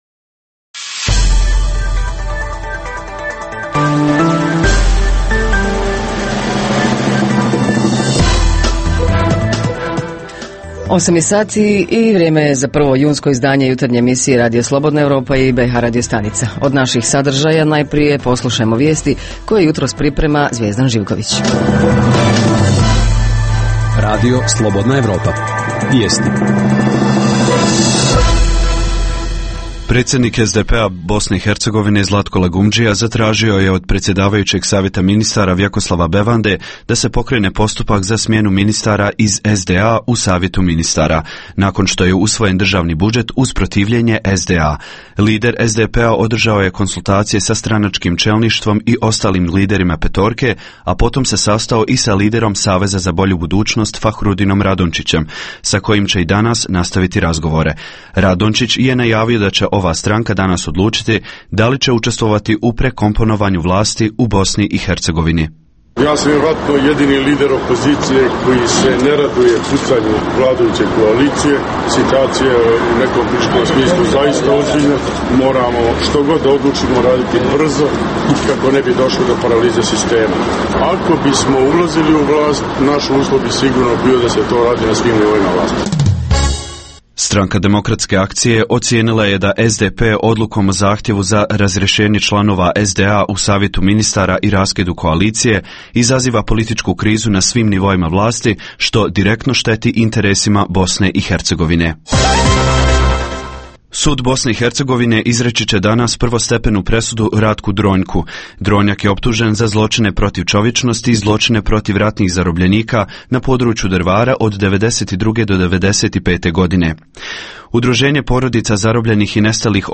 U jutarnjoj emisiji najavljujemo: Od danas u Sarajevu "Projekt Velikog Pariza", a tim povodom gost nam je Almir Šahović, bivši ambasador BiH u Francuskoj, sada član organizacionog odbora i portparol ovog projekta.